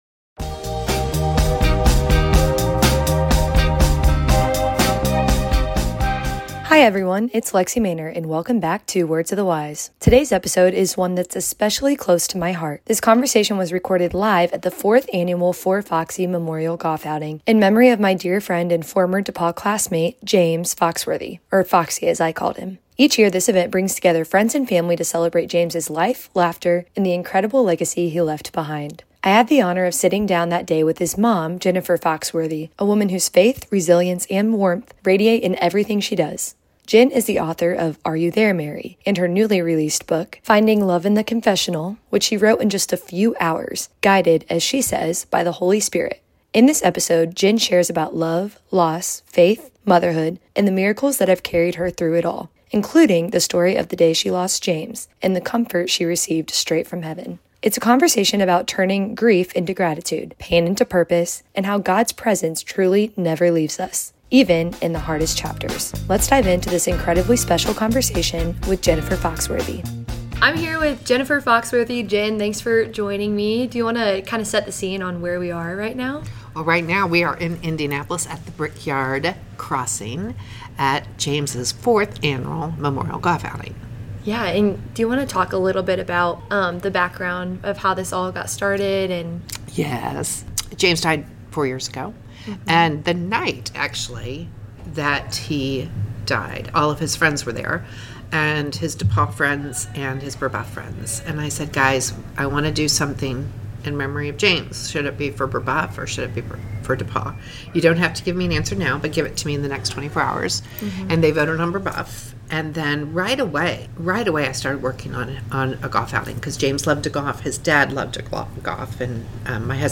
In this hope-filled conversation